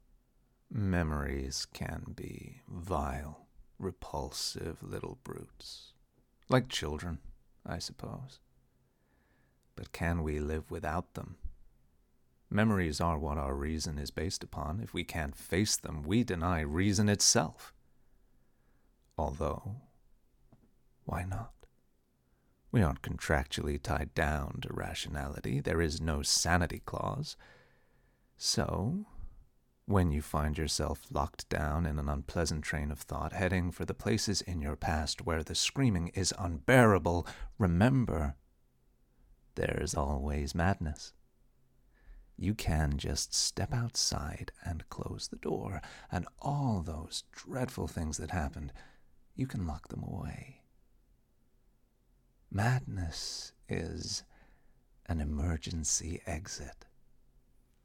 American accent (Colorado)
American-Colorado-Accent-Sample.mp3